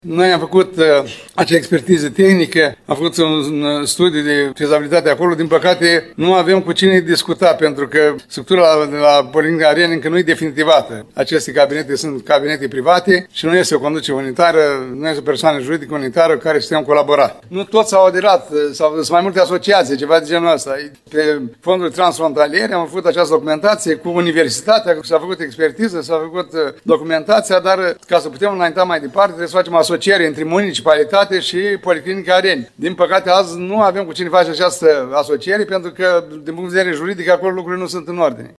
Primarul ION LUNGU a declarat astăzi că studiul de fezabilitate realizat de specialiștii Universității Ștefan cel Mare nu poate fi pus în practică deoarece medicii nu sunt constituiți într-o asociație.